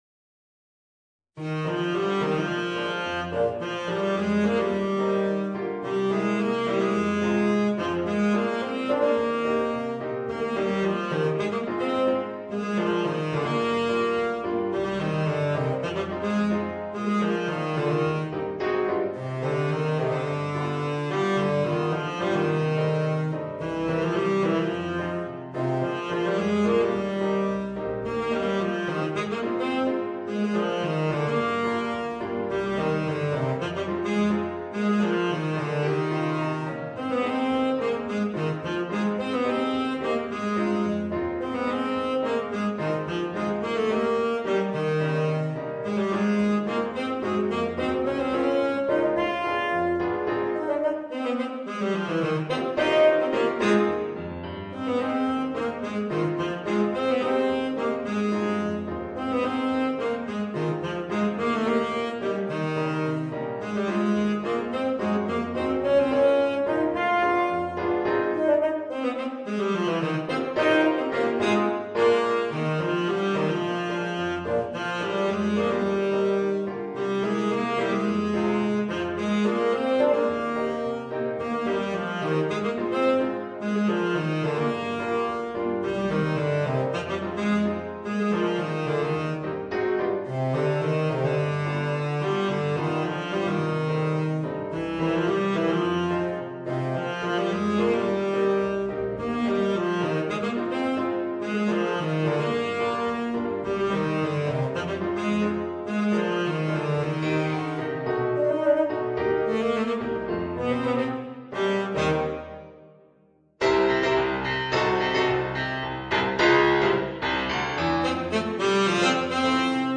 Voicing: Tenor Saxophone w/ Audio